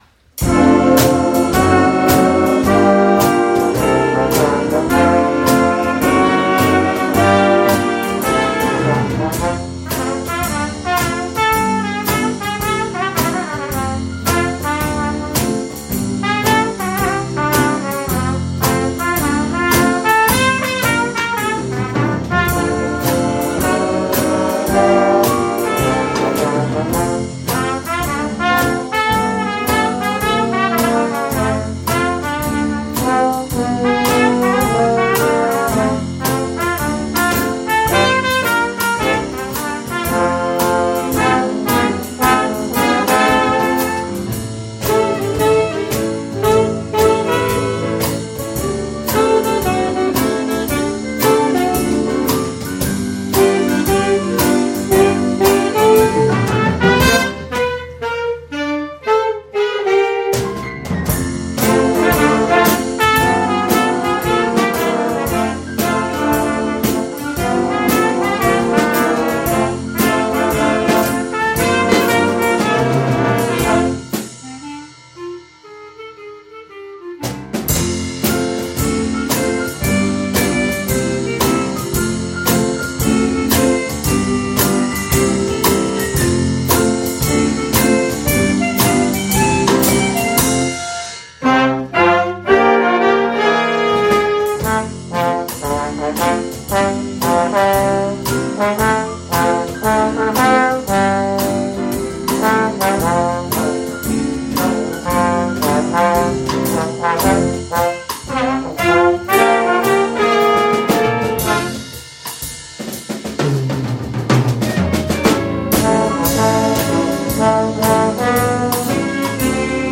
Wir sind trøt, die Jazzband unserer Schule. Aktuell sind wir 20 Musiker*innen der Klassen 9-13. Saxofone, Klarinetten, Trompeten, Posaunen, Drums, Percussion, Piano, E-Gitarren und E-Bass sind am Start.
Wir spielen jazzige Songs, Soul und Pop.